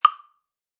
5. 計時器的聲音在這裡：
6. 計時器的聲音是60BPM